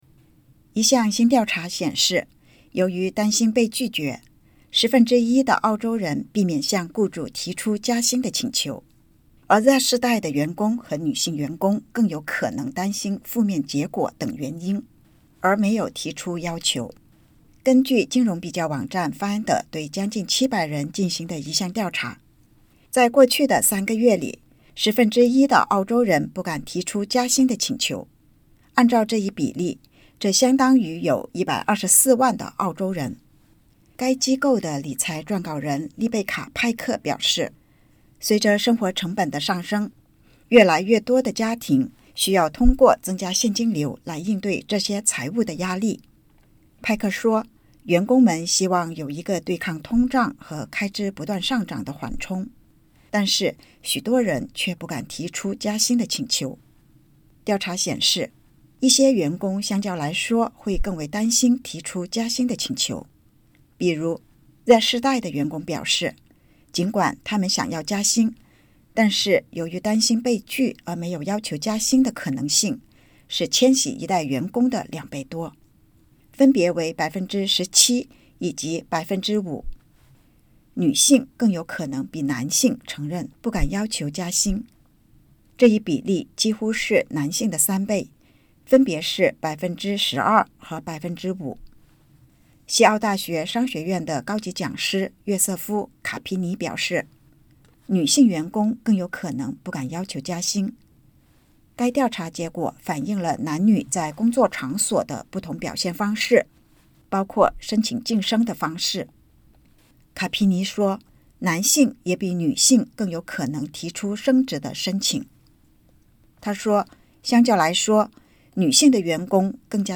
根据澳大利亚一项新调查，Z世代员工和女性员工更有可能担心负面结果等原因，而不敢向雇主提出加薪要求。（点击播放键收听报道）